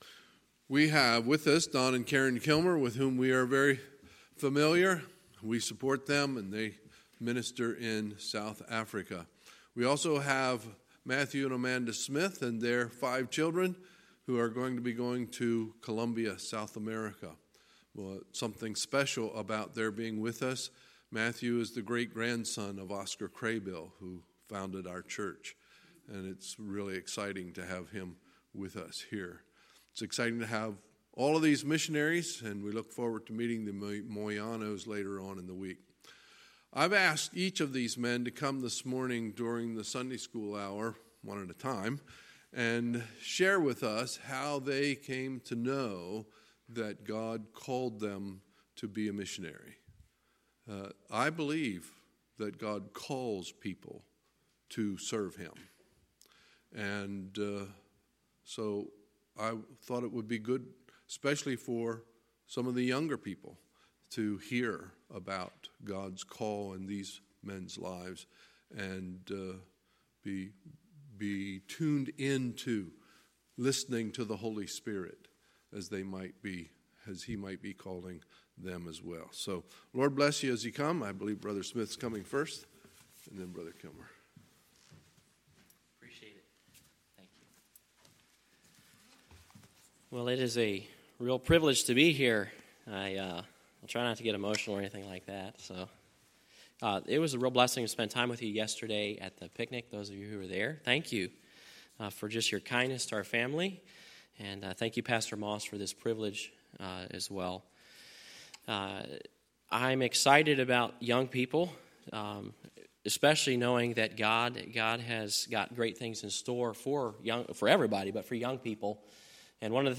Sunday, September 22, 2019 – Missions Conference Session 1